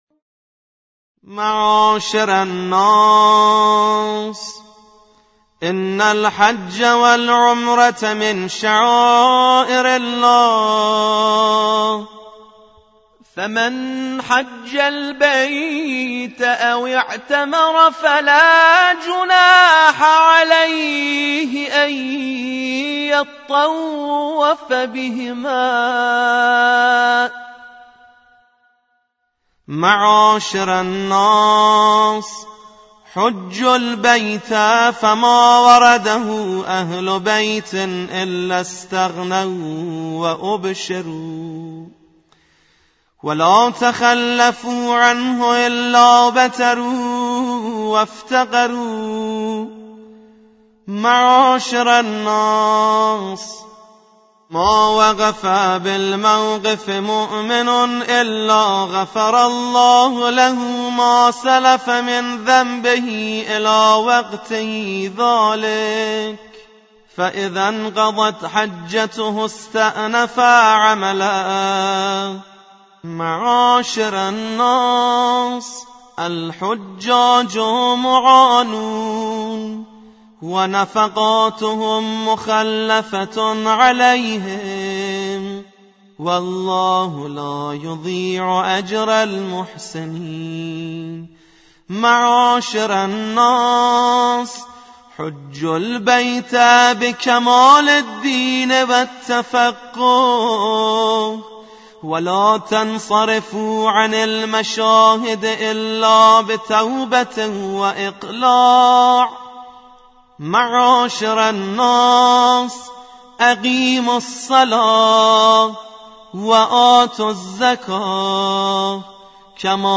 قرائت خطبه غدیر